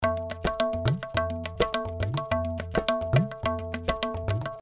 Tabla loops 105 bpm
indian tabla loops at 105 bpm
This is an Indian tabla drum loops (scale A), playing a variety of styles.
Played by a professional tabla player.
The tabla was recorded using one of the best microphone on the market, The AKG C-12 VR microphone. The loops are mono with no EQ, EFFECT or DYNAMICS, but exported stereo for easy Drop and play .